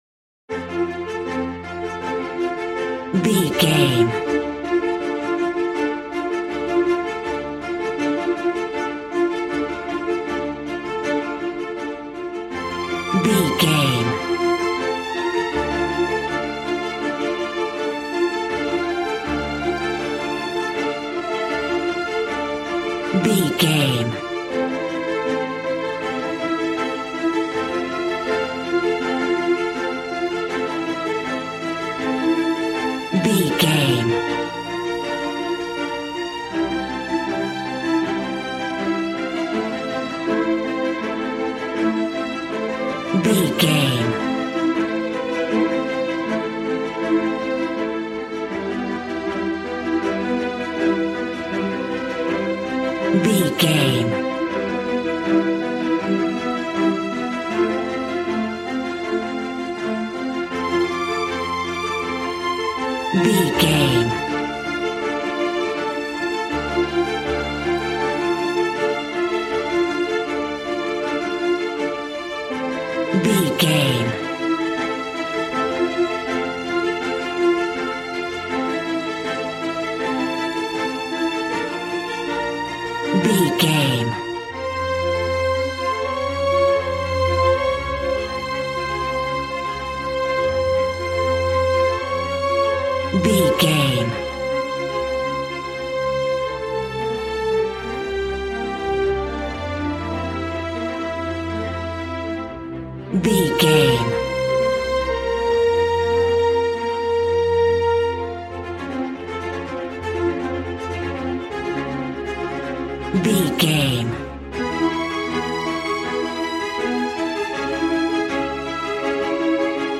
Regal and romantic, a classy piece of classical music.
Aeolian/Minor
regal
cello
violin
strings